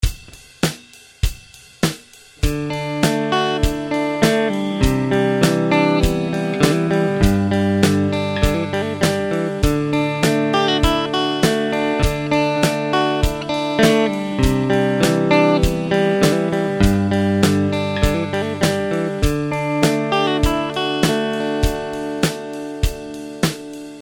Next we will vary the arpeggios further by throwing in some licks based on other variations of the chord that use hammer-ons and pull-offs .
D C G Open Chord Arpeggios With Licks | Download